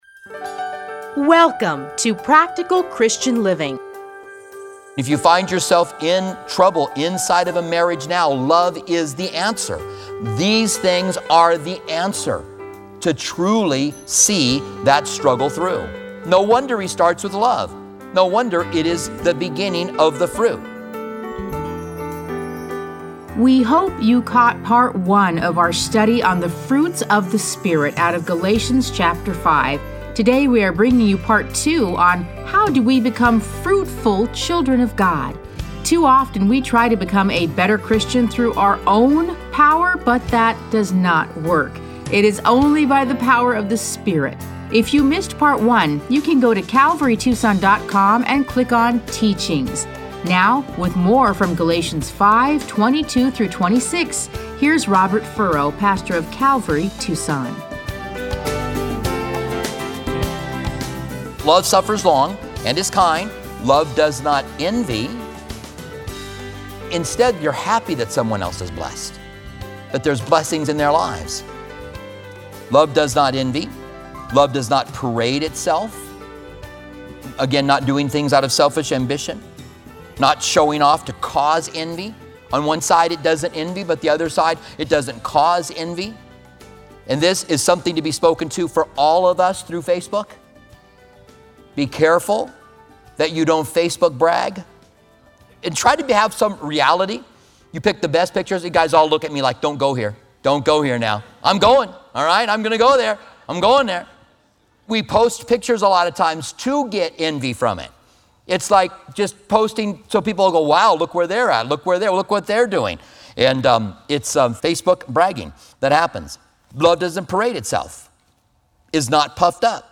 Listen to a teaching from Galatians 5:22-26.